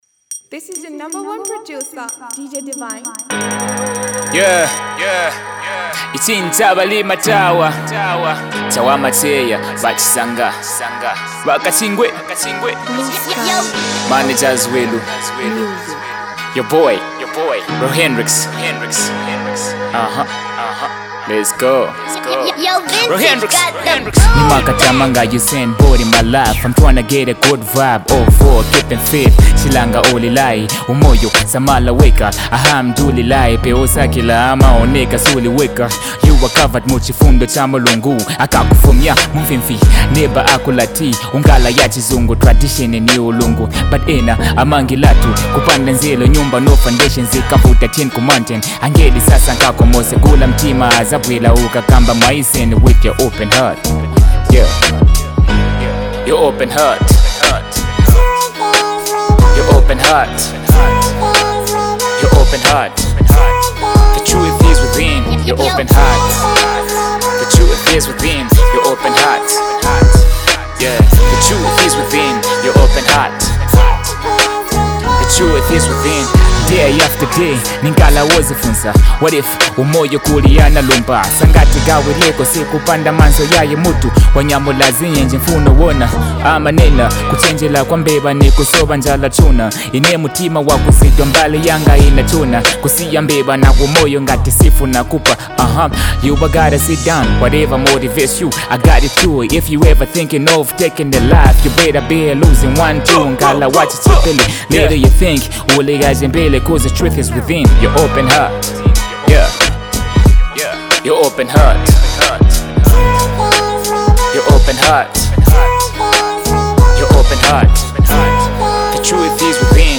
Zambian Rapper-songwriter and performer
With its captivating melodies and thought-provoking lyrics